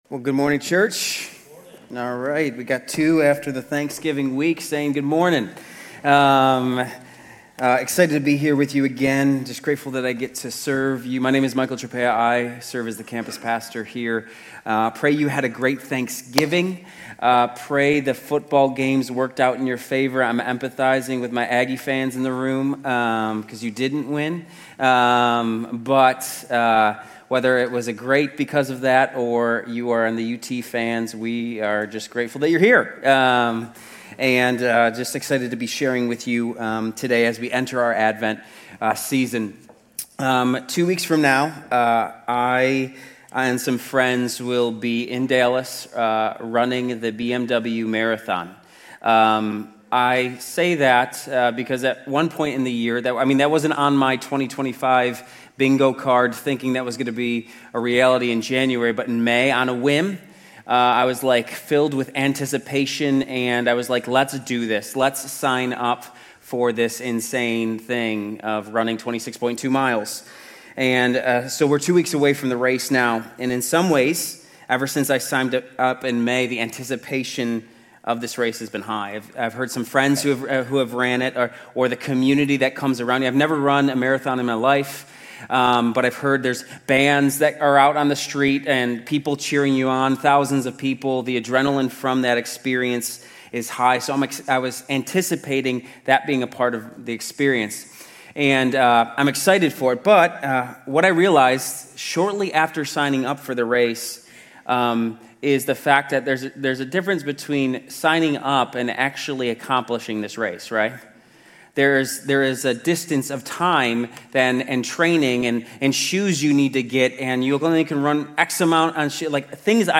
Grace Community Church University Blvd Campus Sermons 11_30 University Blvd Campus Dec 01 2025 | 00:32:37 1x 00:00 / 00:32:37 Subscribe Share RSS Feed Share Link Embed